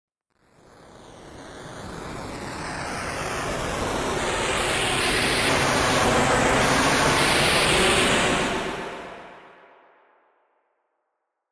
دانلود صدای باد 18 از ساعد نیوز با لینک مستقیم و کیفیت بالا
جلوه های صوتی
برچسب: دانلود آهنگ های افکت صوتی طبیعت و محیط دانلود آلبوم صدای باد از افکت صوتی طبیعت و محیط